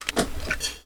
use_medkit.ogg